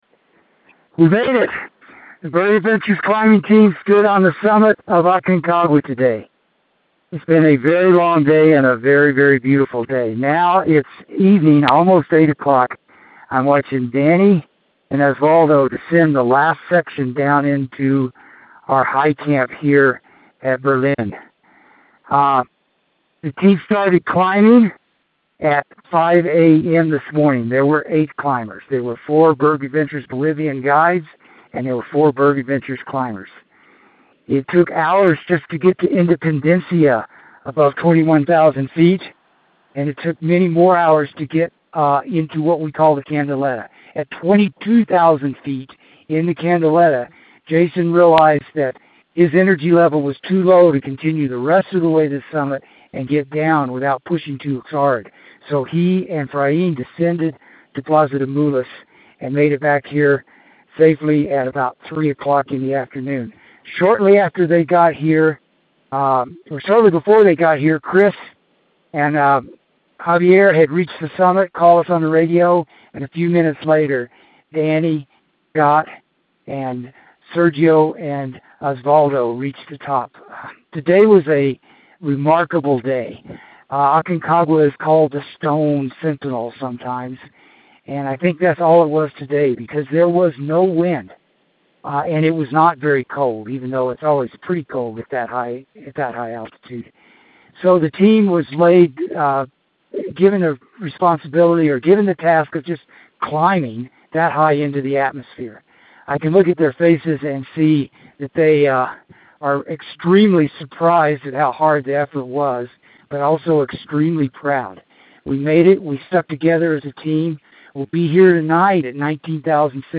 Aconcagua Expedition Dispatch